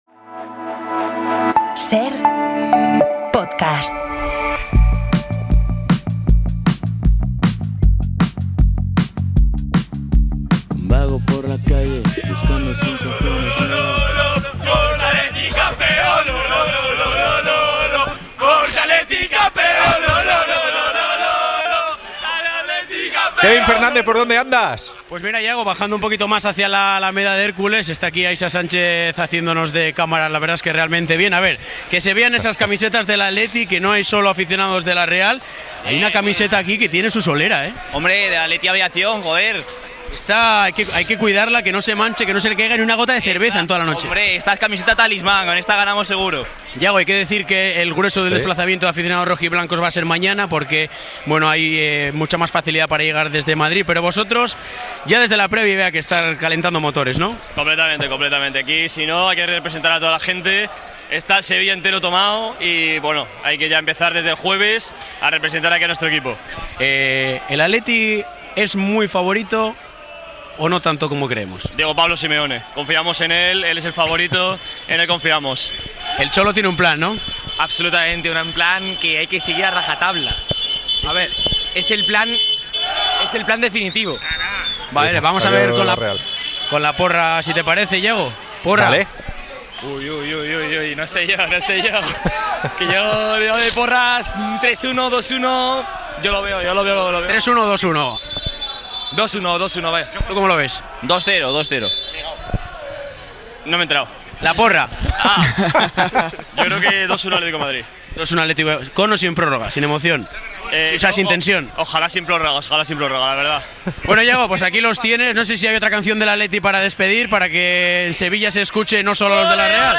Kiko Narváez y Bakero hacen el análisis de la final y entrevista a Rafa Louzán